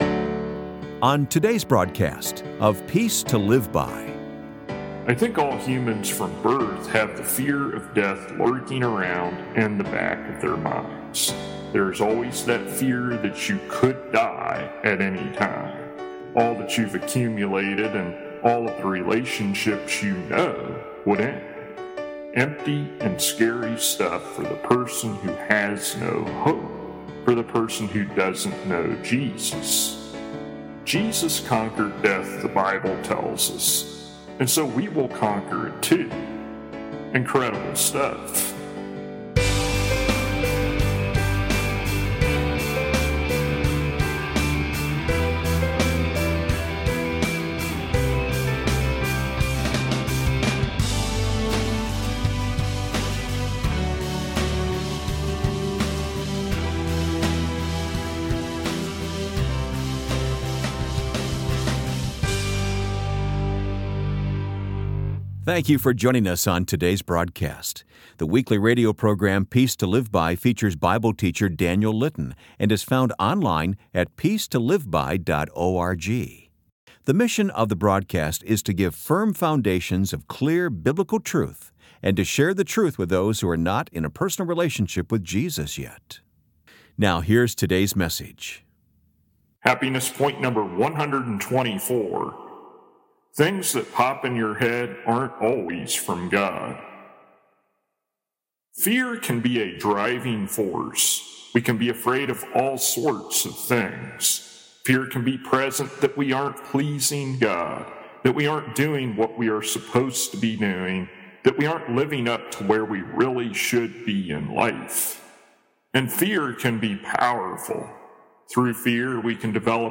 [Transcript may not match broadcasted sermon word for word]